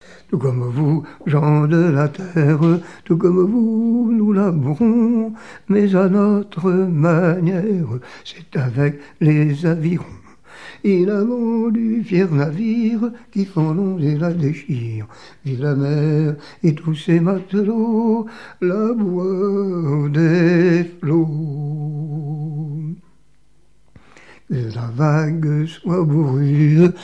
Genre strophique
témoignages et chansons maritimes
Pièce musicale inédite